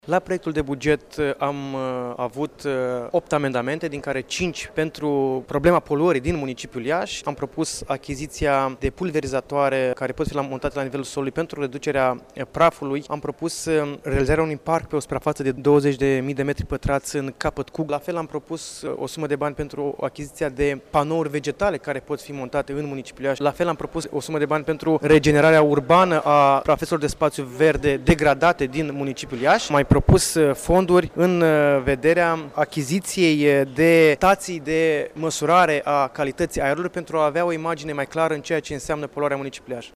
După 4 ore de dezbateri, bugetul municipiului Iaşi a fost aprobat, astăzi, în şedinţa Consiliului Local.